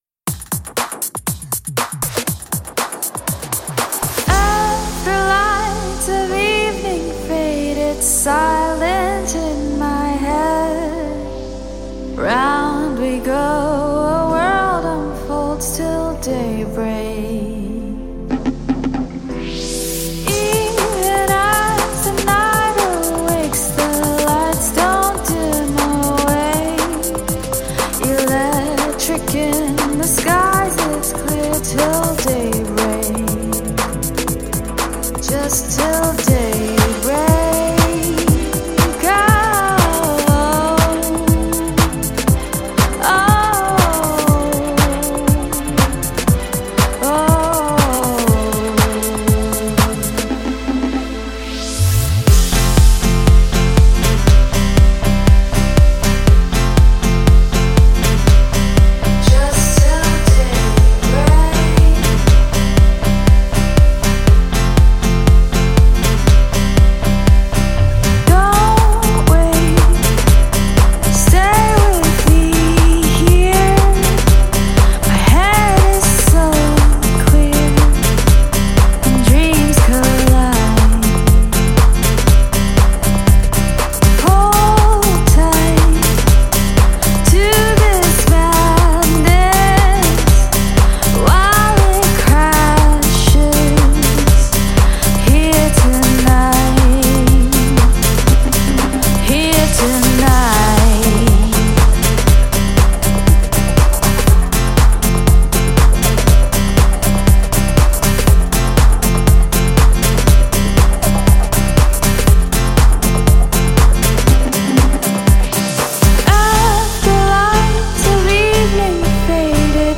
Žánr: Electro/Dance